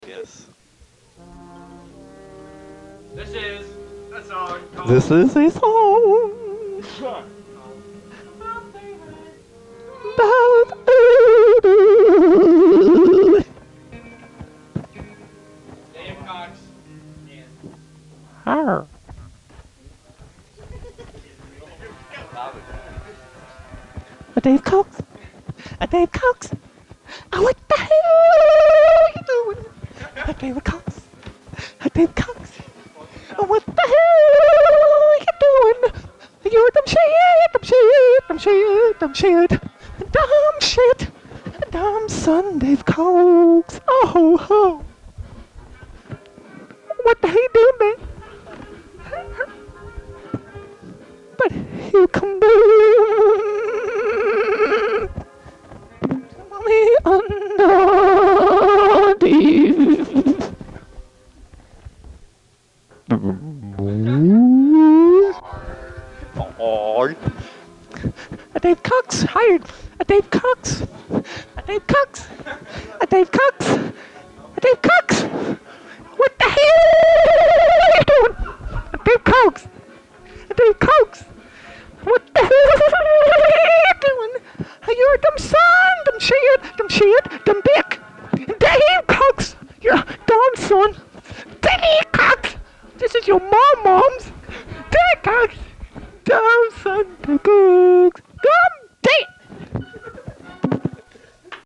isolated vocal